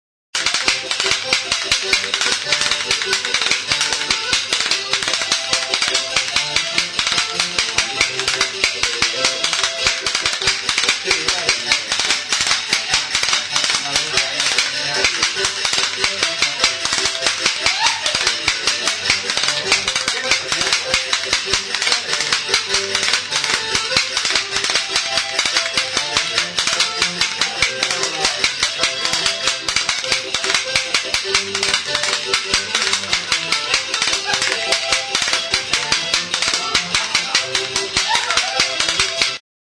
Idiophones -> Struck -> Indirectly
EUROPE -> EUSKAL HERRIA
Kanabera pitzatua da. Astintzerakoan kalaka gisa funtzionatzeko alde batean beheragune (errebaje) bat dauka.